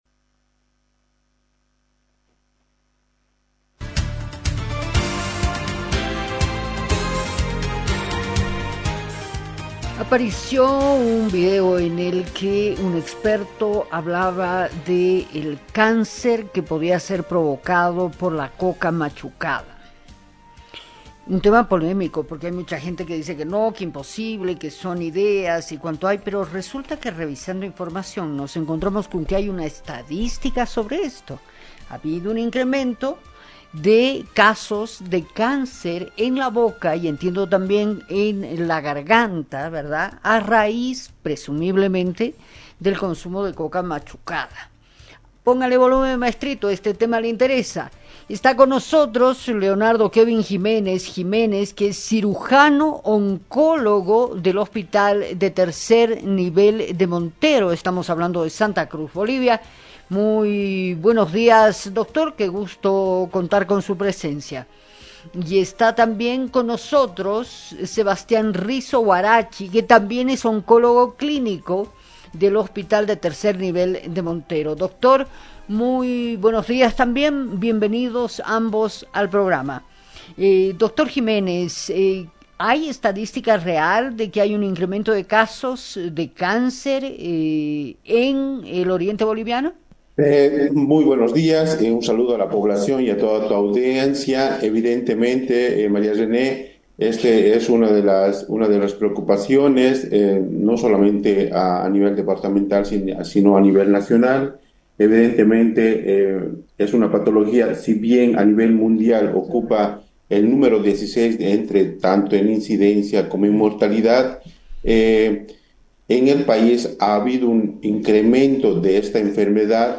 En este episodio, nos adentraremos en el mundo del acullico y su relación con el cáncer bucal. Hablaremos con expertos y analizaremos cómo las tradiciones pueden impactar nuestra salud.